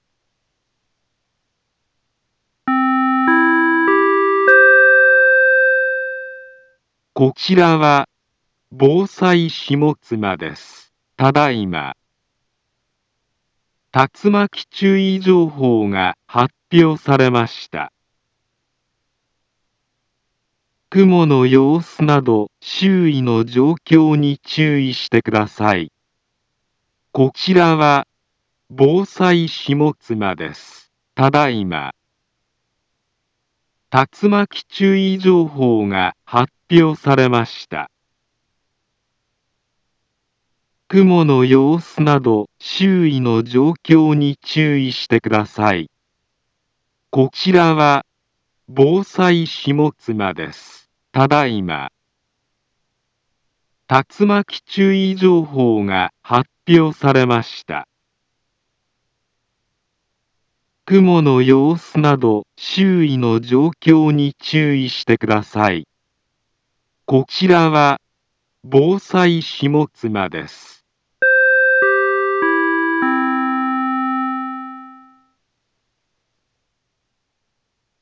Back Home Ｊアラート情報 音声放送 再生 災害情報 カテゴリ：J-ALERT 登録日時：2023-08-01 11:39:49 インフォメーション：茨城県南部は、竜巻などの激しい突風が発生しやすい気象状況になっています。